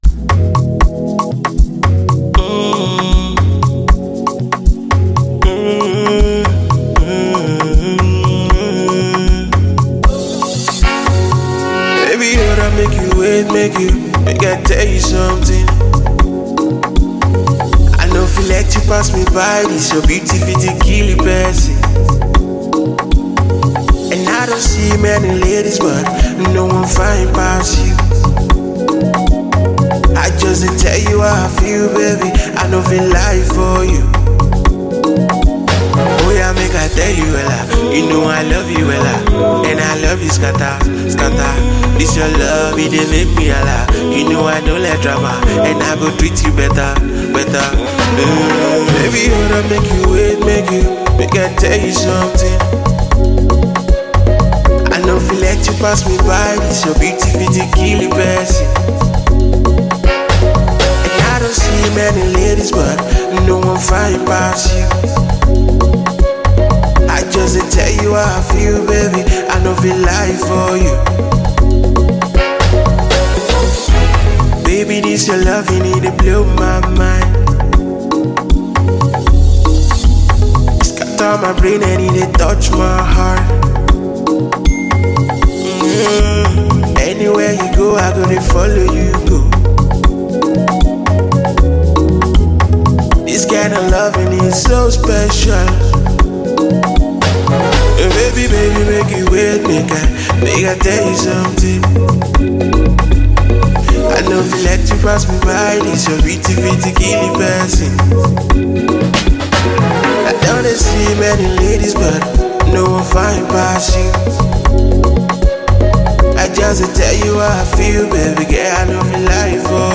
With its infectious hook and irresistible bounce